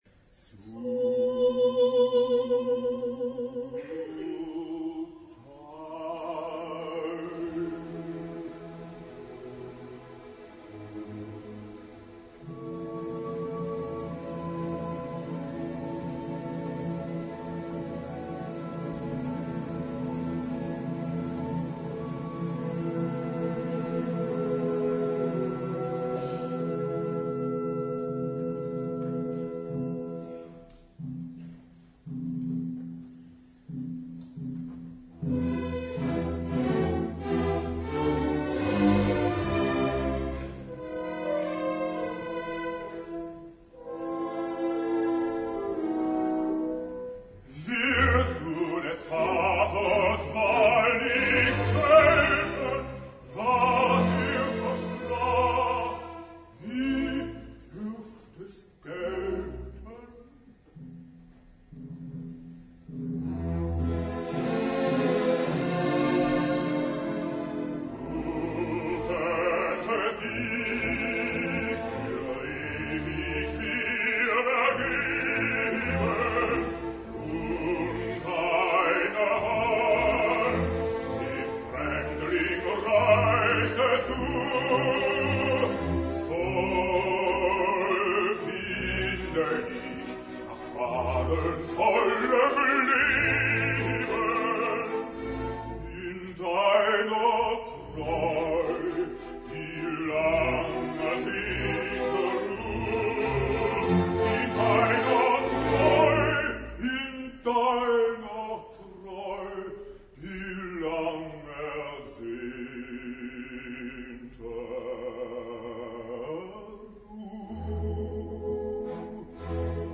Ópera romántica en 3 actos.
Nuestra intérprete de Senta es nada menos que ASTRID VARNAY, cantante lírica de impecable trayectoria, habiendo abarcado tanto el registro de soprano dramática como el de mezzosoprano.